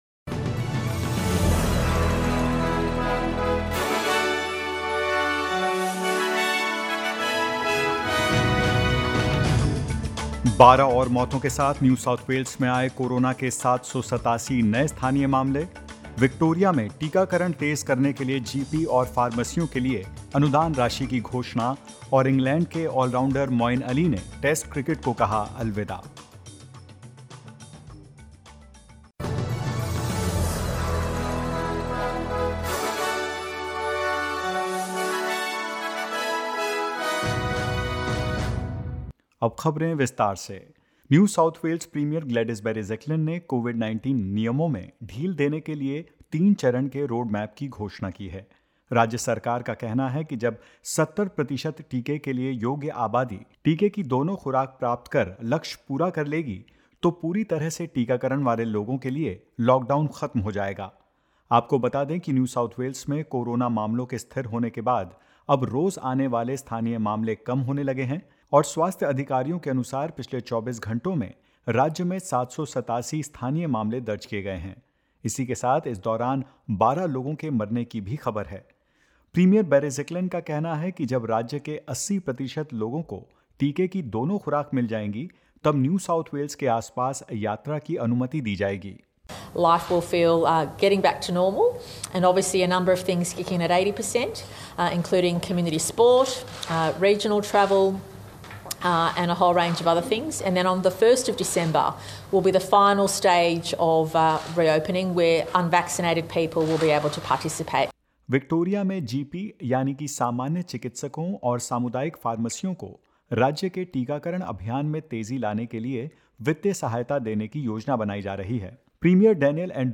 In this latest SBS Hindi News bulletin of Australia and India: Victoria announces grants for GPs and pharmacies to boost vaccination effort; ACT records 19 new locally acquired cases and one death and more.